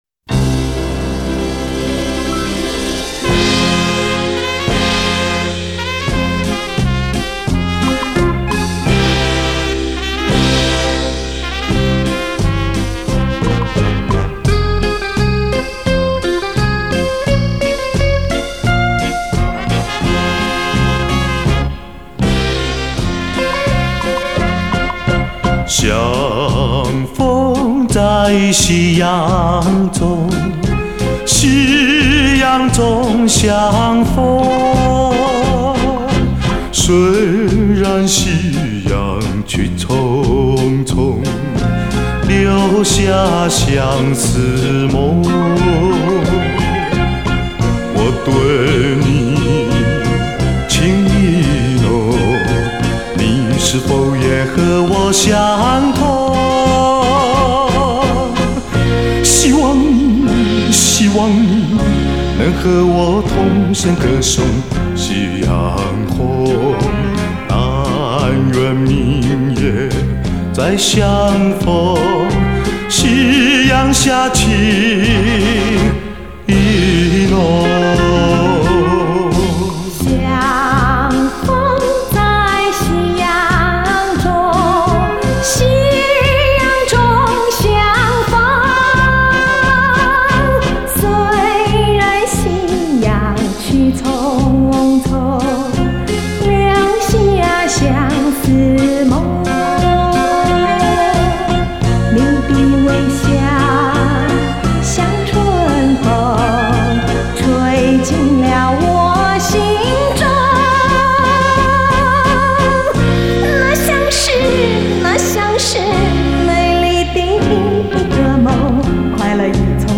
雨天的歌声雄健厚实 从粗狂豪野，到款款情深，都表达的淋漓尽致!